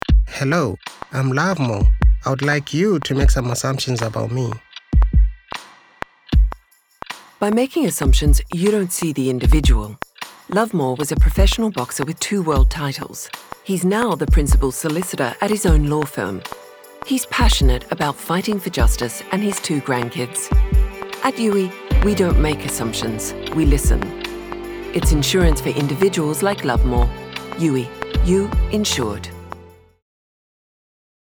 Led by simple, uncluttered TVCs, and supported by social stories and polls and 30 second radio spots, we put the challenge out for people to make some assumptions about the individual before them.